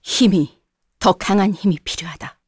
Seria-Vox_Victory_kr.wav